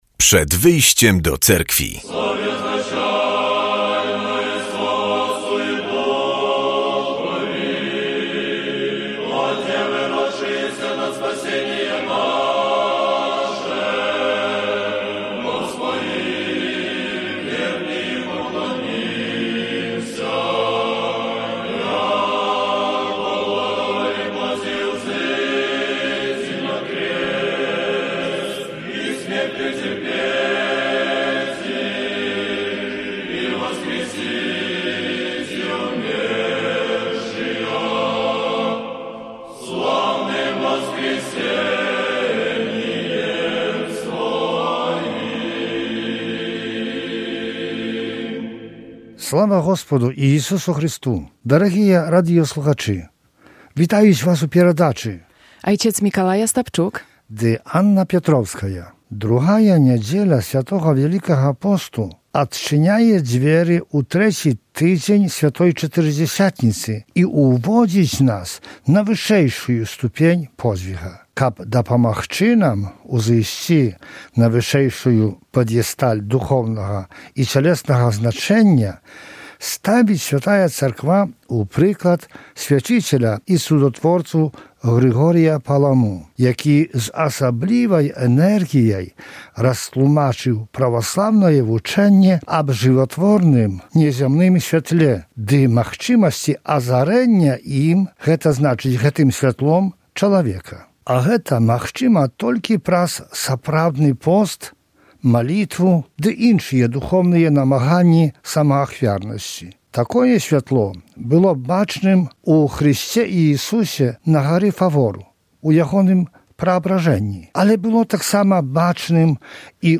W audycji usłyszymy kazanie na temat niedzielnej Ewangelii i informacje z życia Cerkwi prawosławnej. Oceniano nie tylko znajomość historii ale także modlitw i struktury nabożeństw.